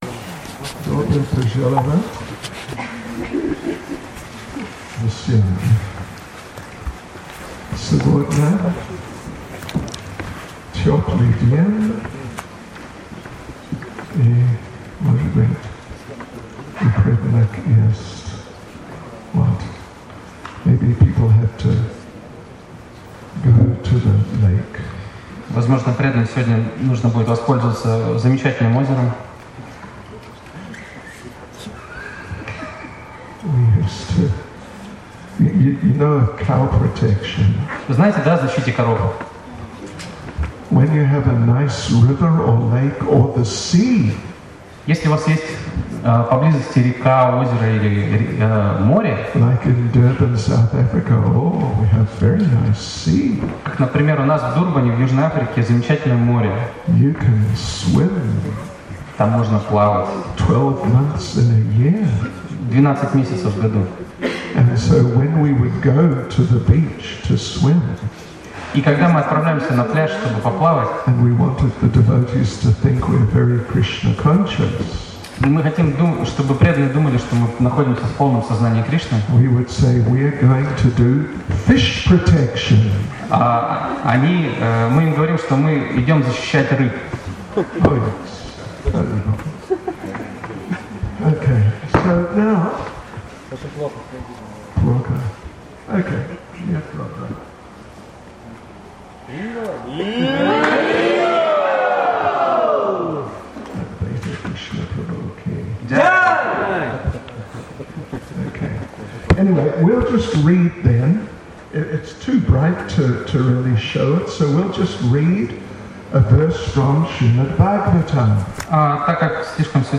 Bhakti Rasa Festival, Russia